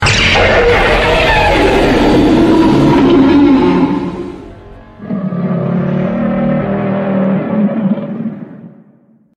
Roplez-Roar.ogg